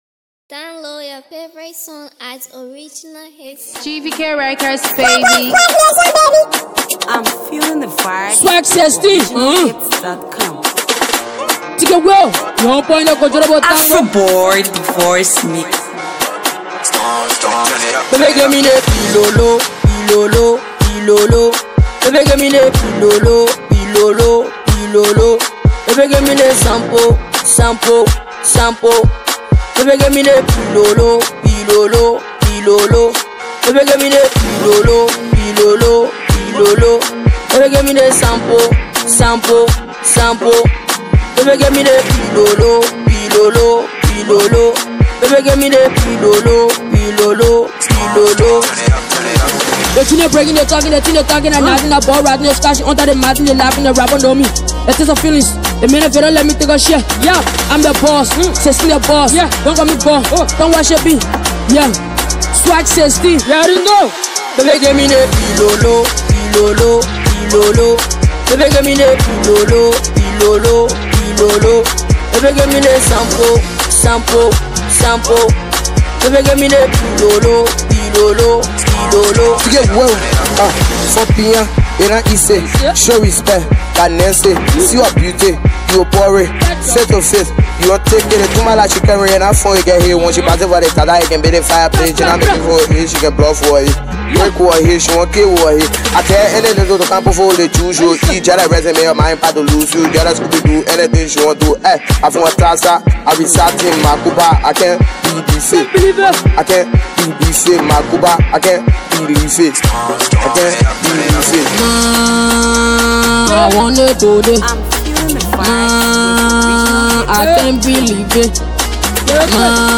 Liberian rap